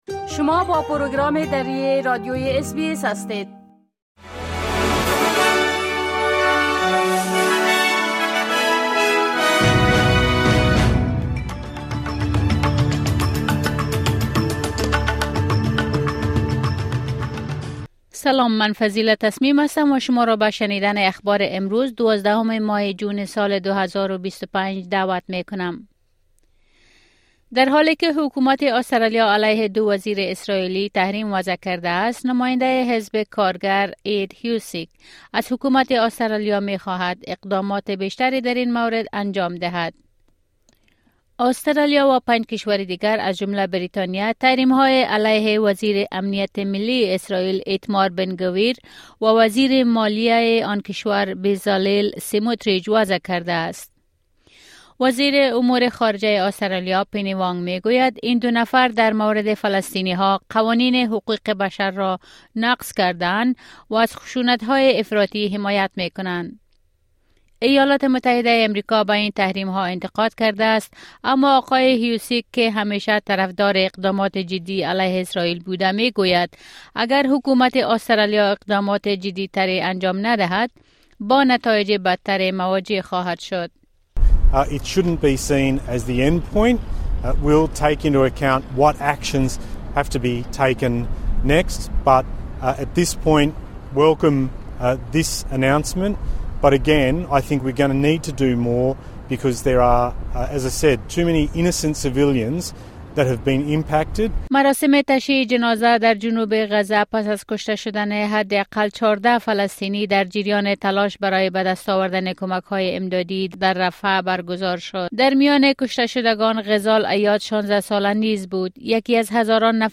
مهمترين خبرهای روز از بخش درى راديوى اس‌بى‌اس | ۱۲ جون